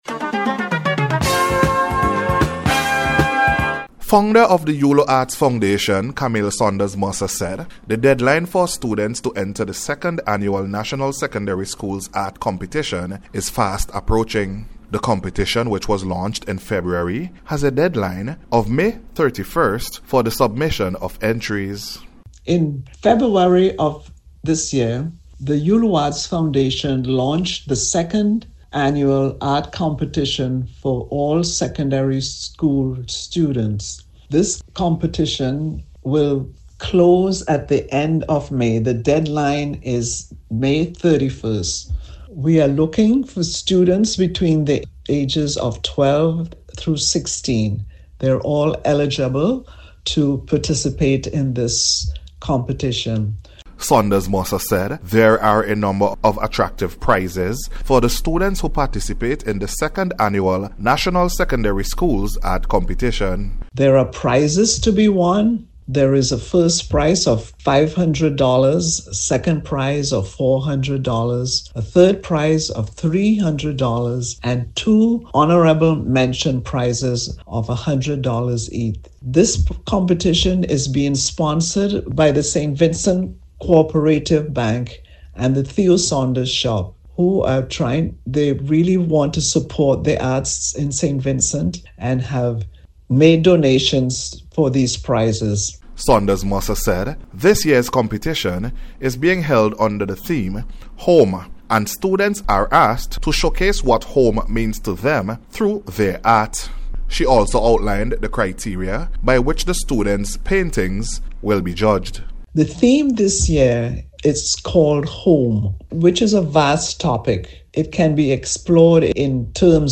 SECONDARY-SCHOOLS-ART-REPORT.mp3